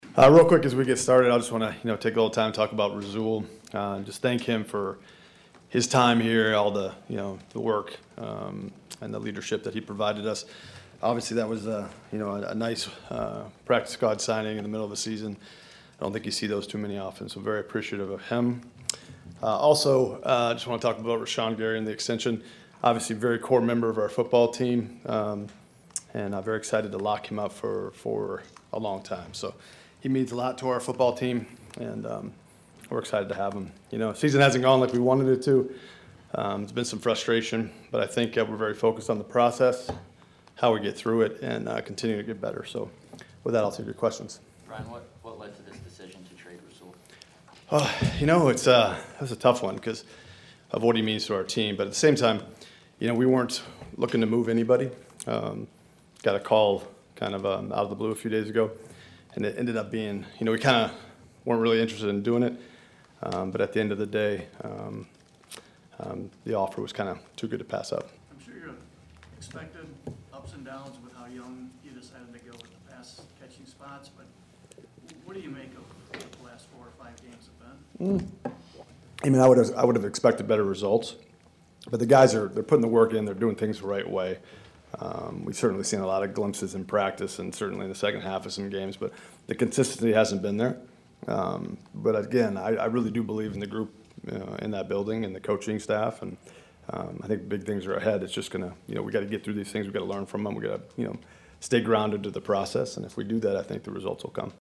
Gutey meets the press at Lambeau.